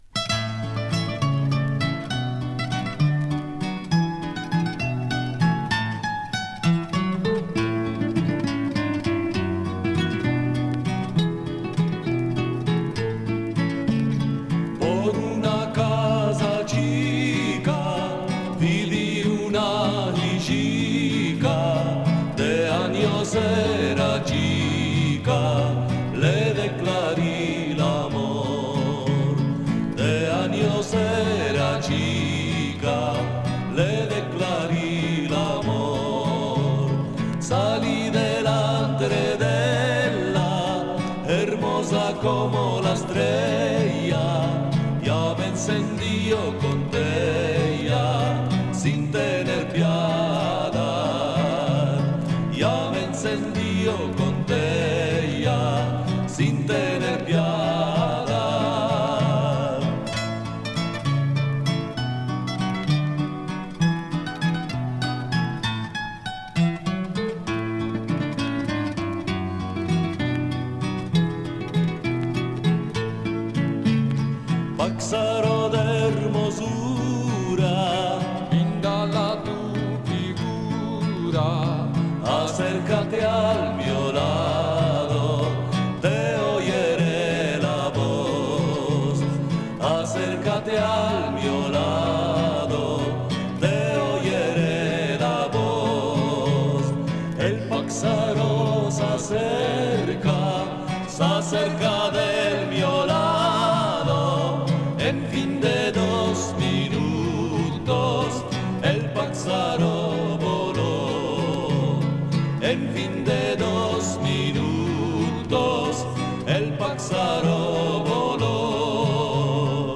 Entdecken Sie eine Auswahl judäo-spanischer Lieder aus unseren Sammlungen, mit welchen Sie die Wartezeit bis zum Schulbeginn in guter Gesellschaft überbrücken können